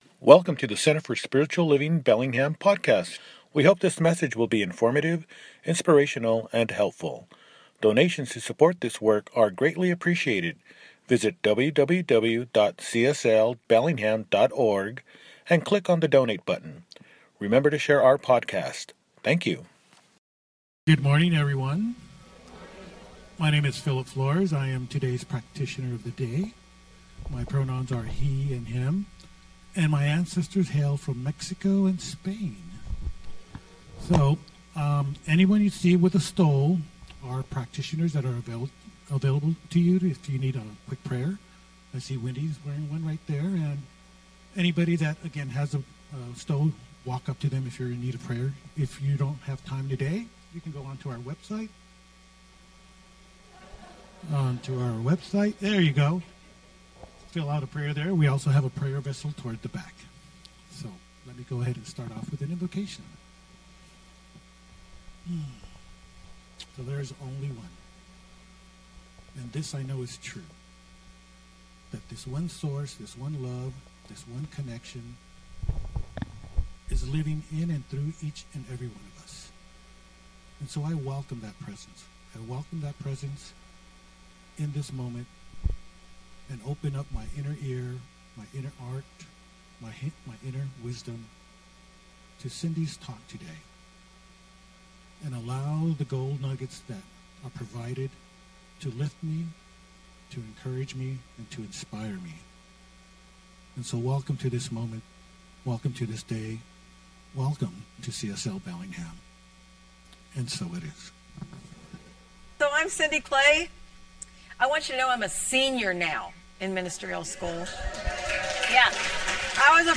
Inner Personal: Living on the Inner Edge – Celebration Service